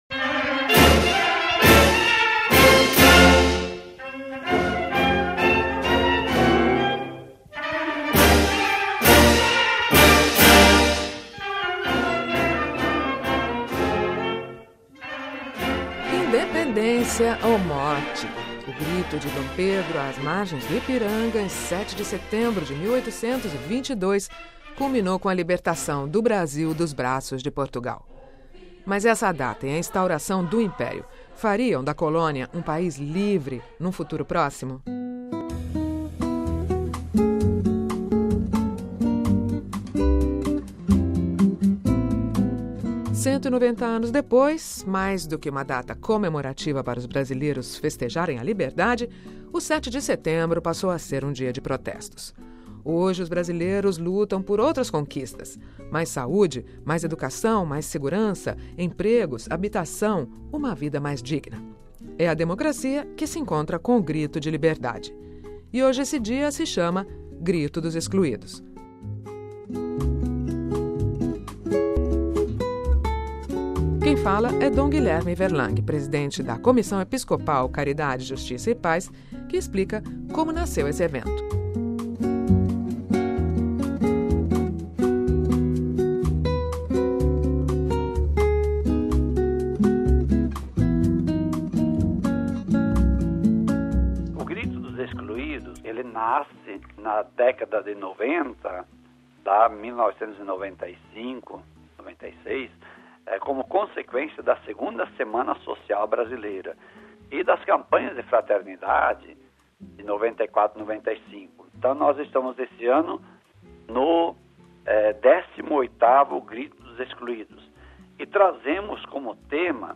Quem fala é Dom Guilherme Werlang, Presidente da Comissão Episcopal Caridade, Justiça e Paz, que explica como nasceu este evento.